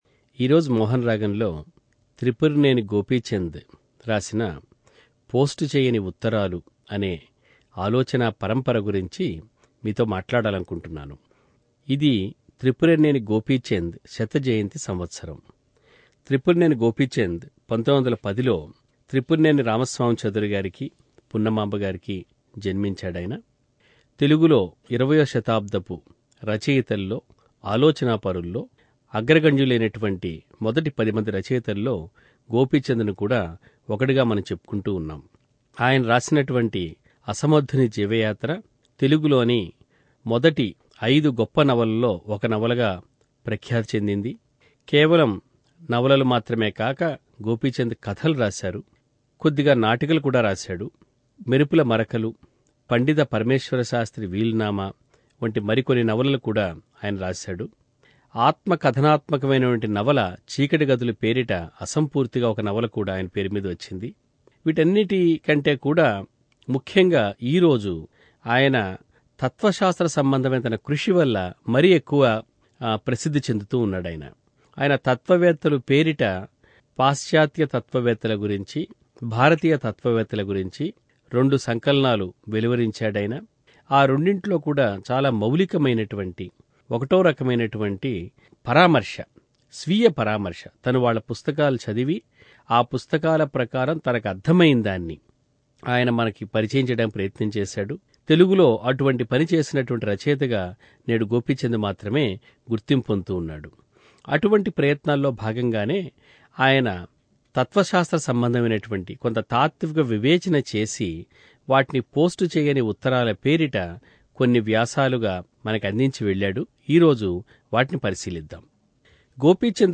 2007 లో చేసిన ప్రసంగం.